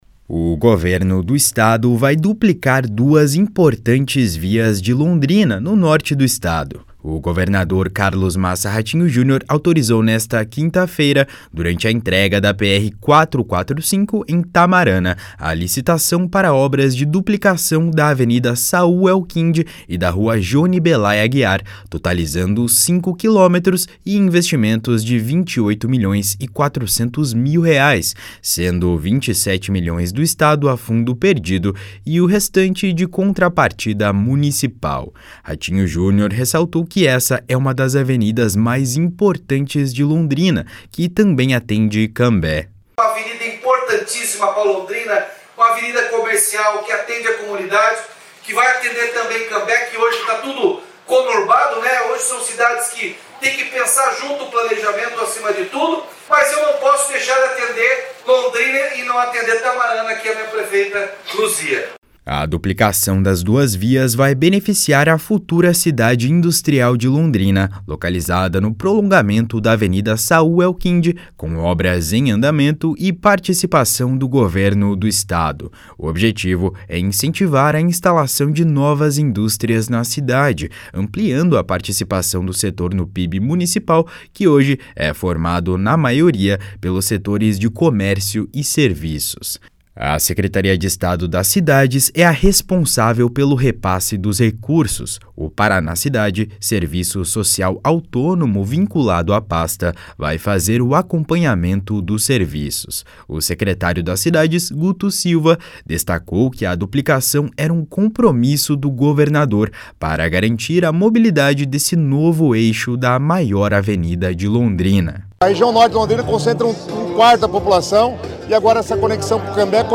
// SONORAR RATINHO JUNIOR //
// SONORA TIAGO AMARAL //